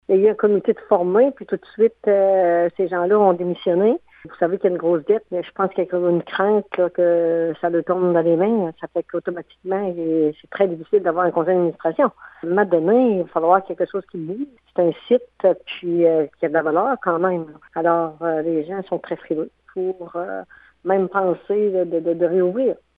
Une tentative de mobiliser des personnes sur le comité a échoué, comme le mentionne la mairesse de la municipalité, Délisca Ritchie-Roussy :